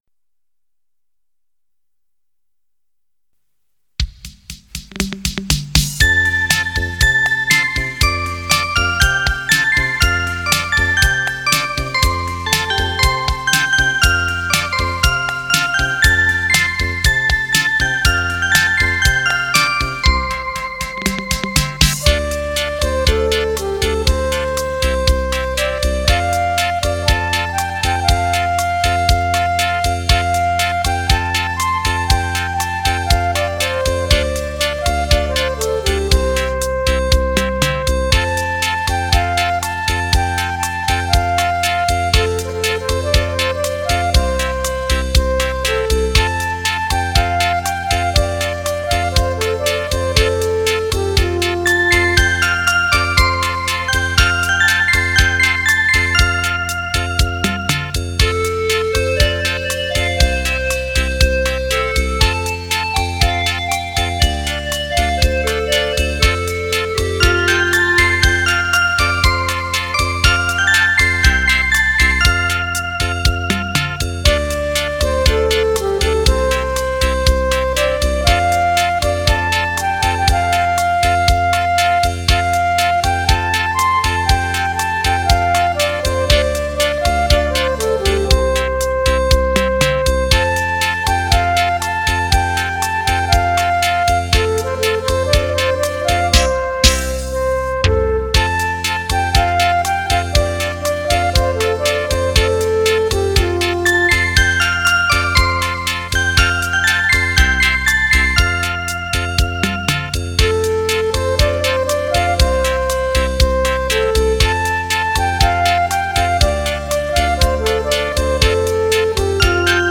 音乐风格：怀旧
悠扬乐韵，展现纯美的音乐质感；
缓慢、抒情、华丽的旋律活泼；
轻快的技巧性，乐缎、丝丝入扣，感人至深……